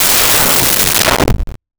Alien Wierdness Descending 01
Alien Wierdness Descending 01.wav